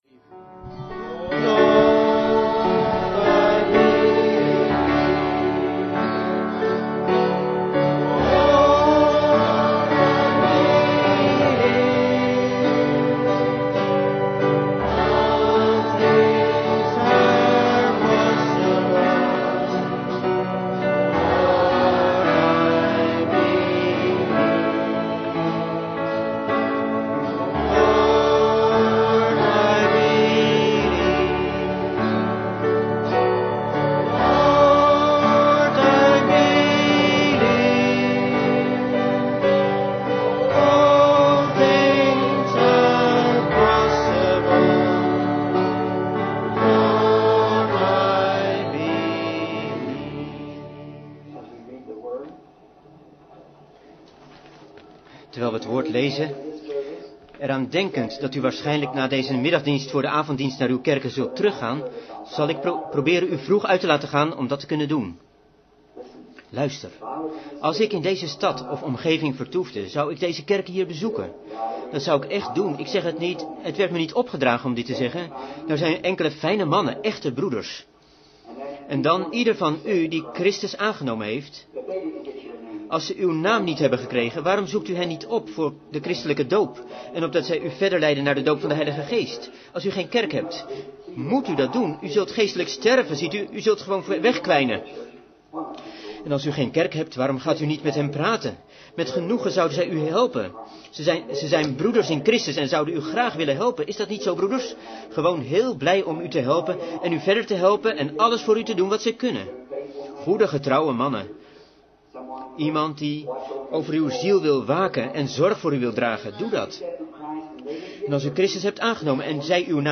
Vertaalde prediking "Identification" door William Marrion Branham te Elliott auditorium, Tulare, California, USA, 's middags op zondag 16 februari 1964